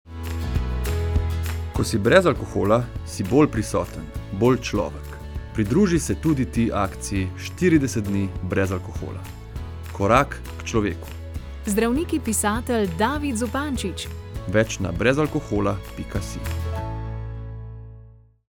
Radijski oglas 20s (WAV)